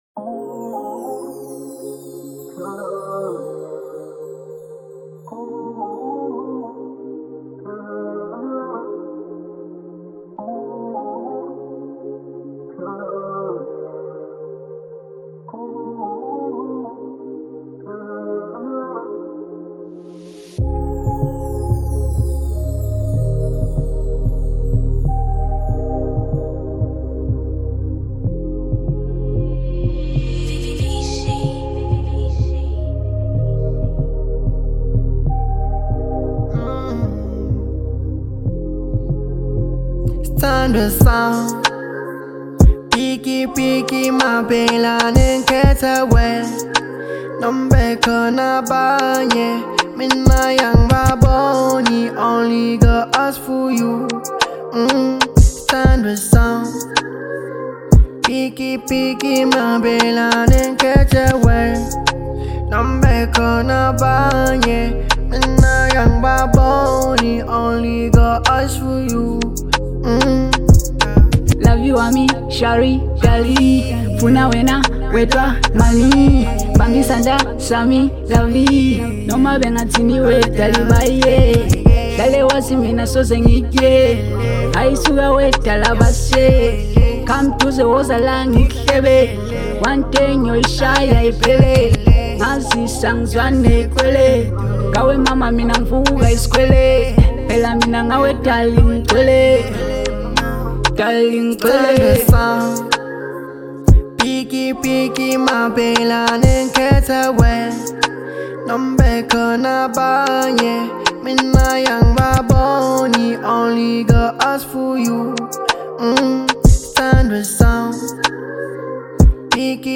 Genre : RnB